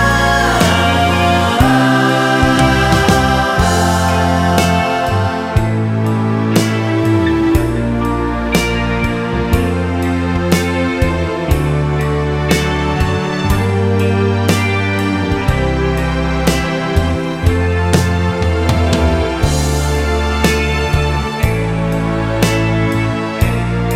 No Piano Pop (1970s) 3:16 Buy £1.50